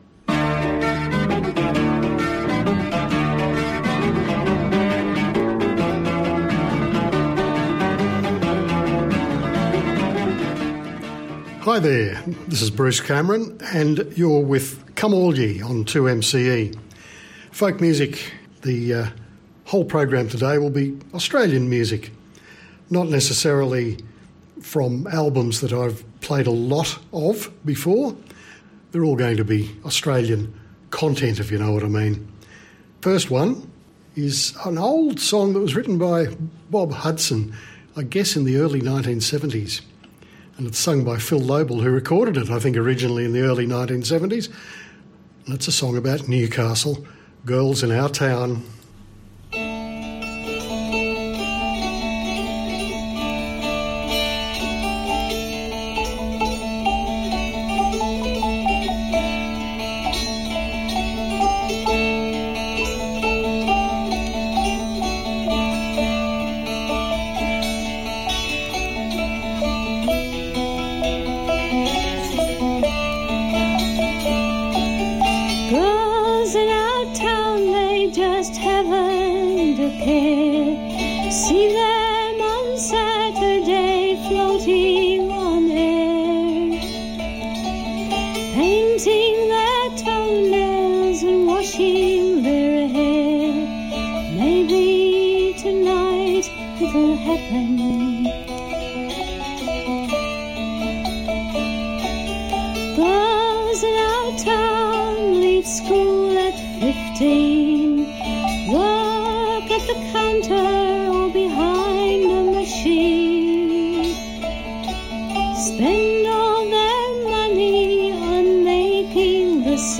Instead of a featured album, the program comprised a mix of Australian tracks.